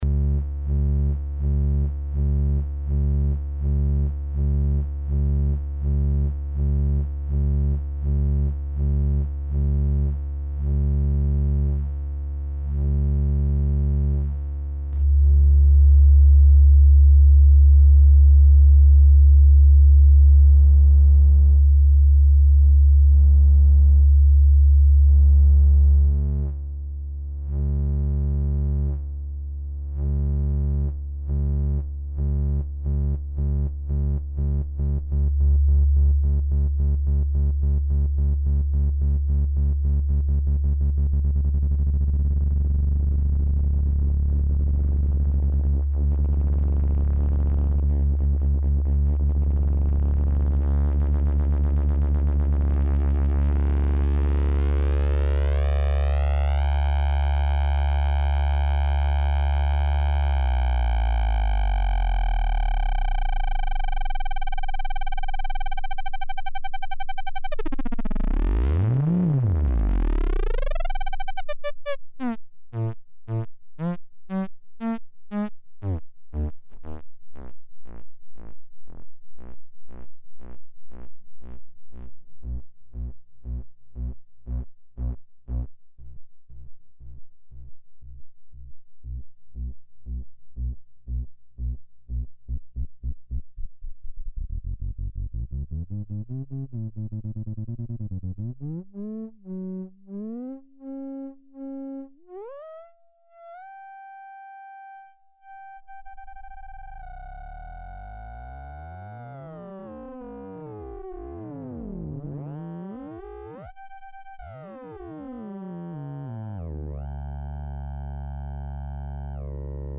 the follw and a pair of TH XRVCOs.
the oscillators are capable of sine, tri and sqr, i switch in that order between them with the most time spent on square. the change between them is kind of obvious because the tri switch pops and is a bit louder coming in, then i pull the plugs out to switch to sqr. i only ever used the same shape on both osc’s at the same time to be boring.
taleof2oscs.mp3